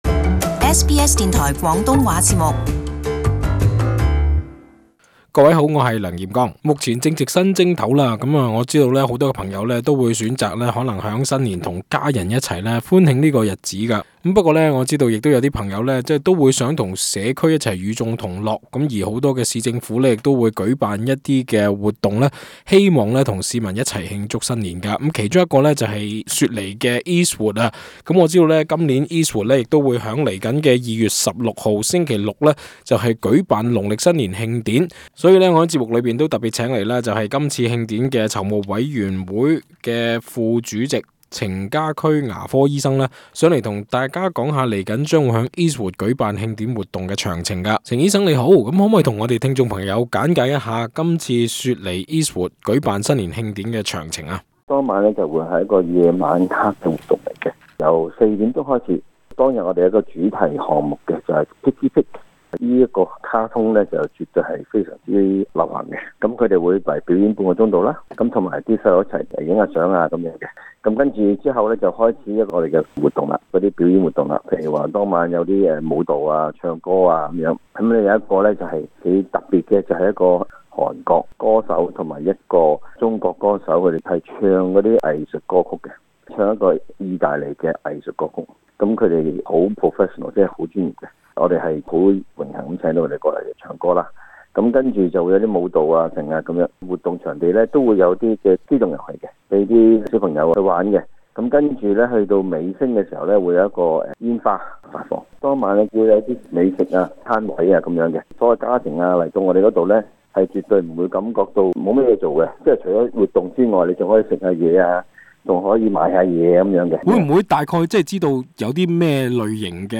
【社區專訪】雪梨伊士活本週六將舉行農曆新年慶典活動